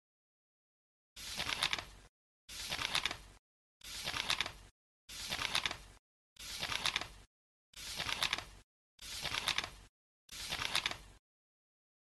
Efek membalik halaman buku.